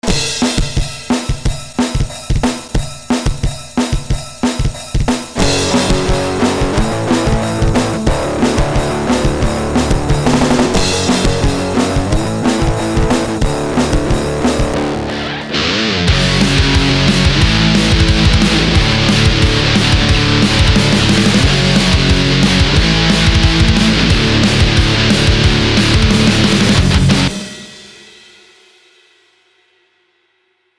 Фрагмент который Вы слышите в начале - был исходником который мне прислали на обработку, по желанию заказчика была произведена перезапись.